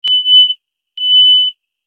دانلود آهنگ کامیون 2 از افکت صوتی حمل و نقل
جلوه های صوتی
دانلود صدای کامیون 2 از ساعد نیوز با لینک مستقیم و کیفیت بالا